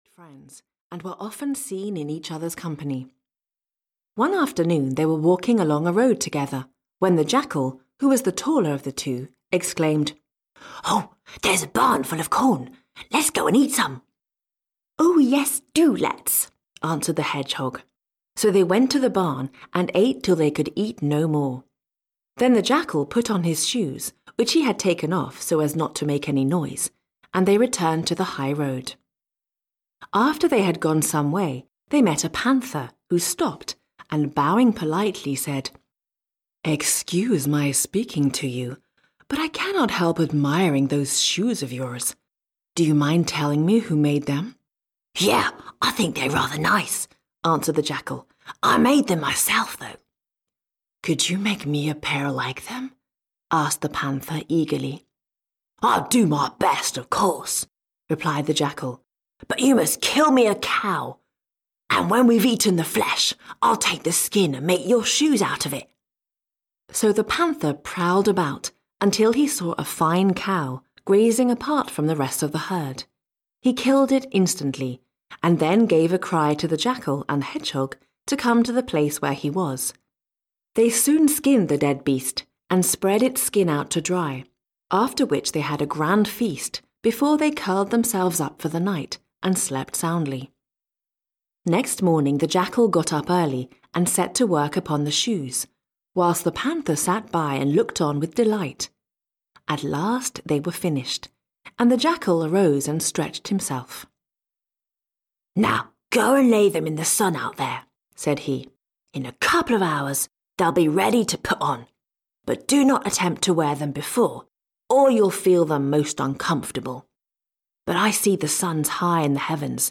Audiobook The Adventures of a Jackal written by J. M. Gardner.
Ukázka z knihy